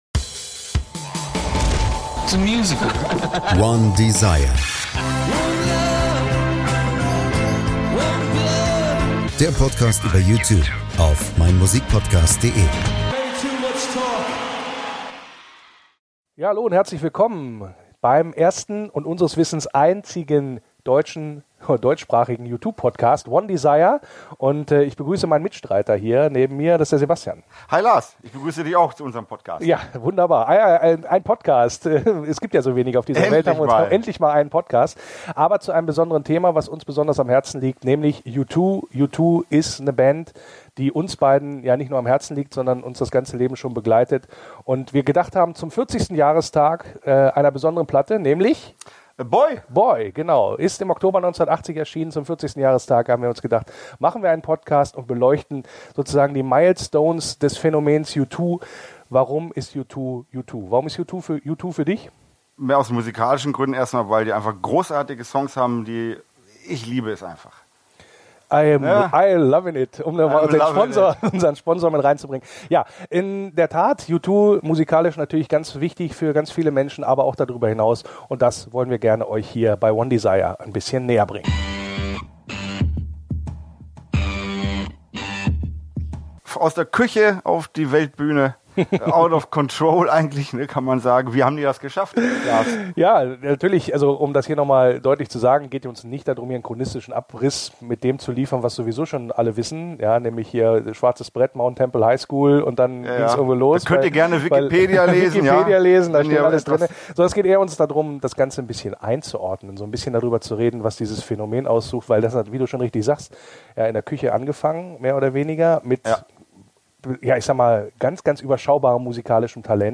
Soundbite Bei one:desire wird auch ganz praktisch zur Gitarre gegriffen und gezeigt, wie sich U2 gerade in der Anfangsphase vom Mainstream in der Musikwelt abhoben, wie der U2-Sound entstand und was sie grundsätzlich von einer Punkband unterschied, die sie eigentlich gern sein wollten.